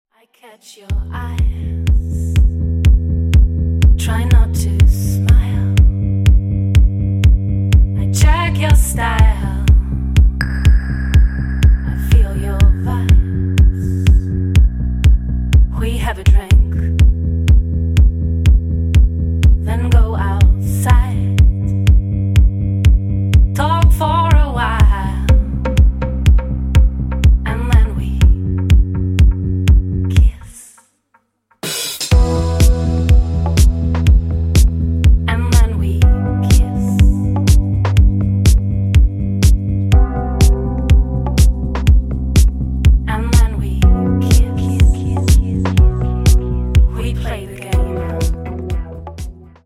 поп
чувственные
красивый женский голос
house